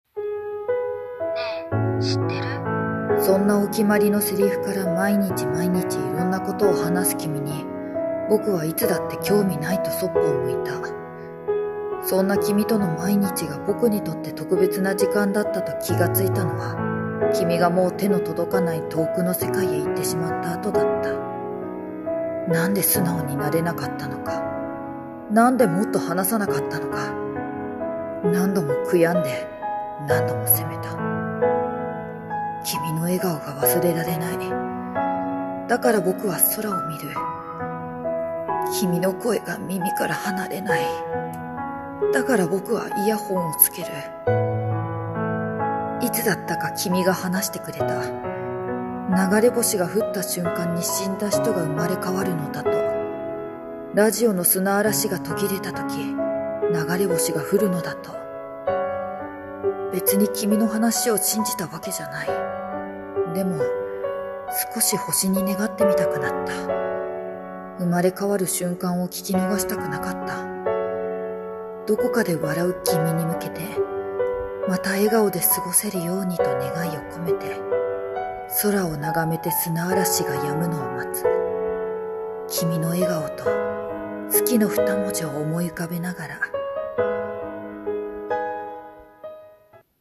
【一人声劇】 君と星と砂嵐と